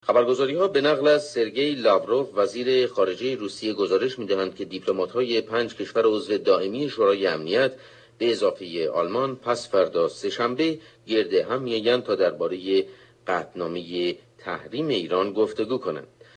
Today we have a recording of a news bulletin from an online radio station for you to identify.